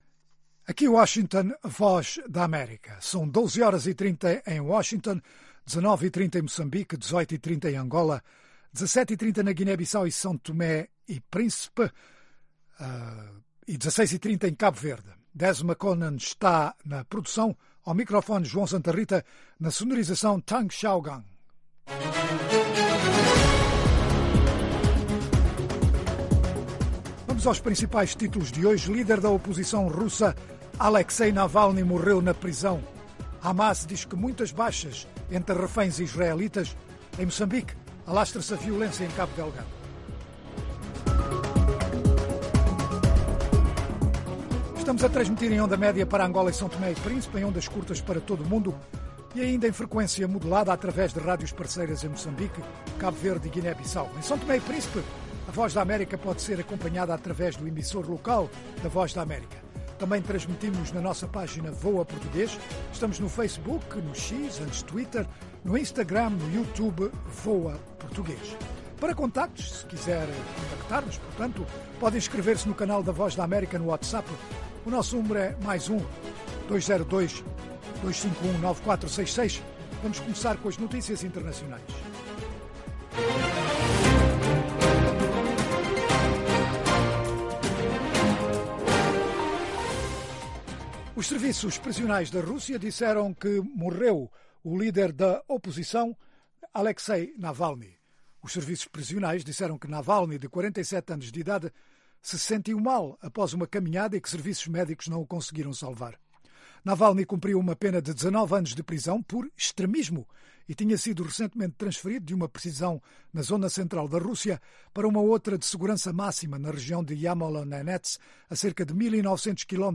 Oferece noticias do dia, informação, analises, desporto, artes, entretenimento, saúde, questões em debate em África. Às sextas em especial um convidado explora vários ângulos de um tema.